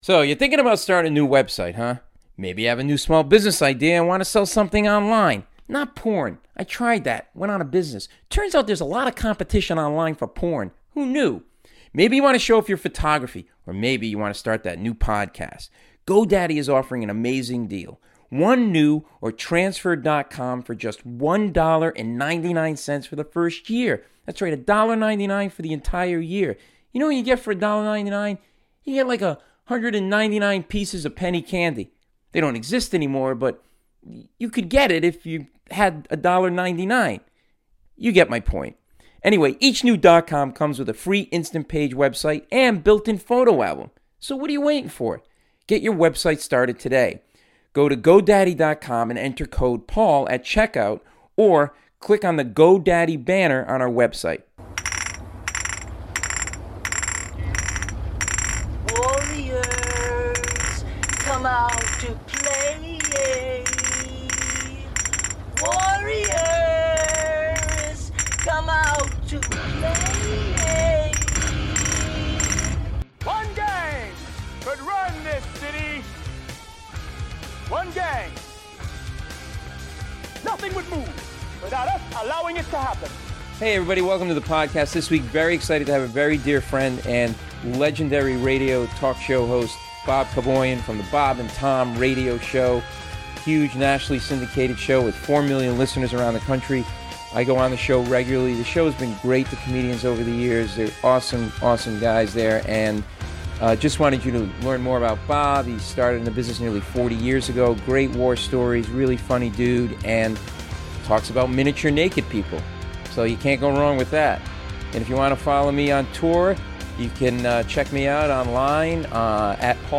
Bob Kevoian, legendary radio personality from the nationally syndicated Bob & Tom Show, is my guest this week. He shares some great radio war stories and talks about whiskey, golf and miniature naked people.